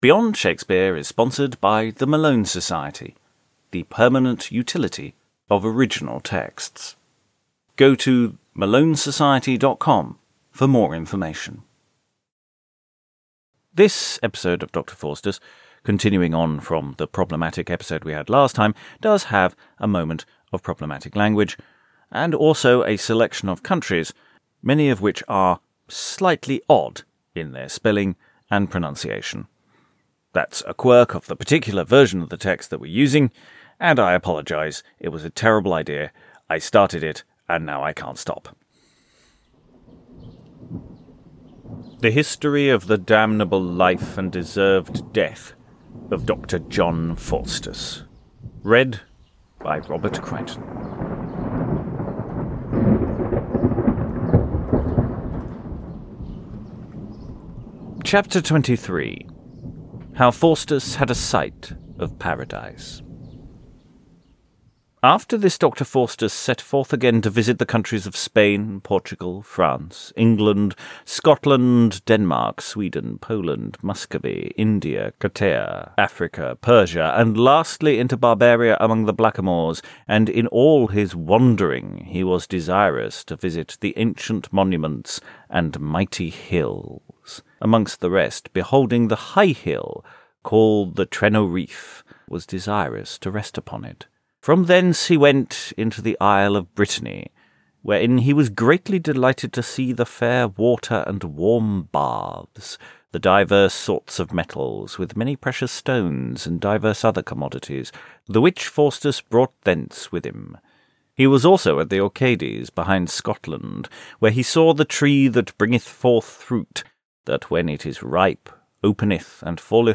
This podcast presents full audio productions of the plays, fragmentary and extant, that shaped the theatrical world that shaped our dramatic history.